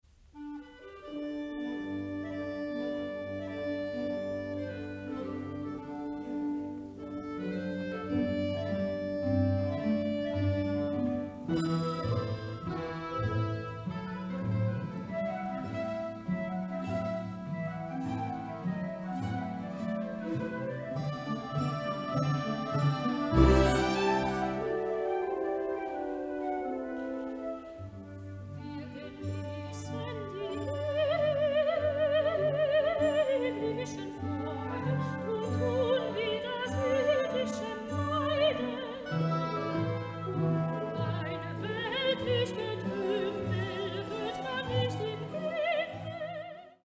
Niebiańskie życie prowadzone jest bardzo wartko.
dysponuje dość małym głosem, który nie zwraca na siebie zbyt dużej uwagi.